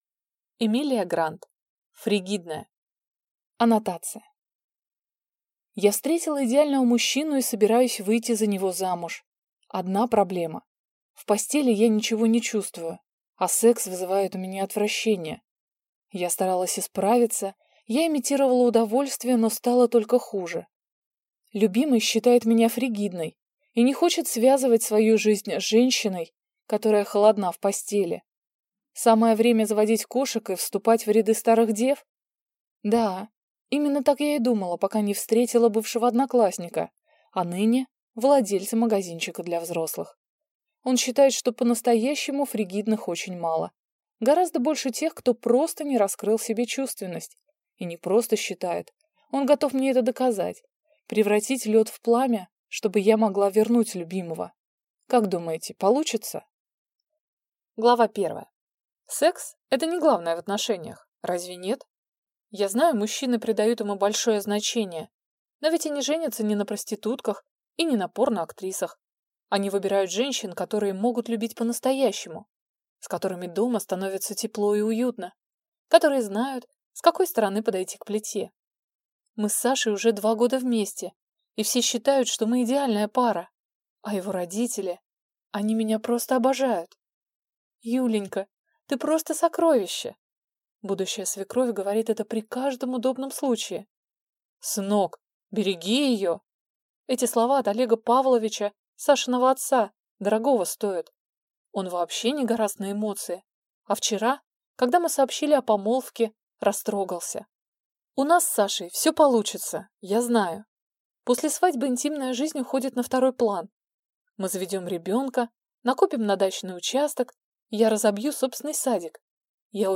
Аудиокнига Фригидная | Библиотека аудиокниг